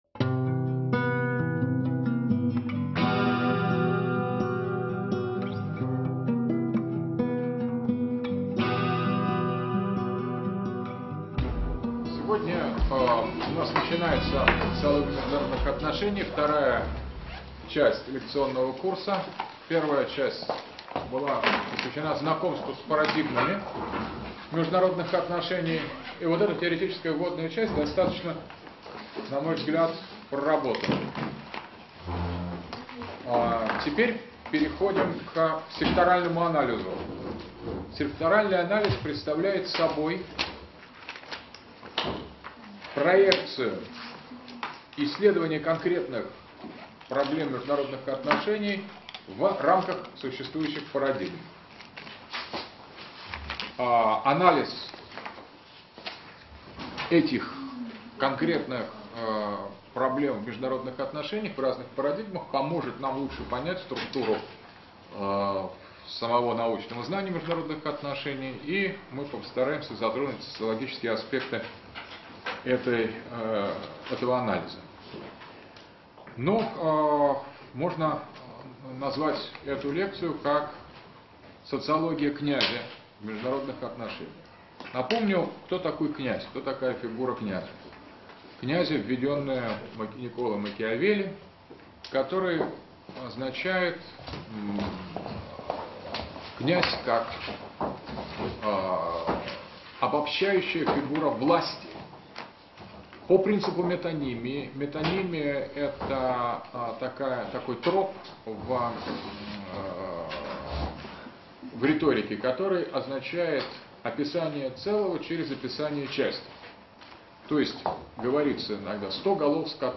Читает А.Г. Дугин. Москва, МГУ, 2012.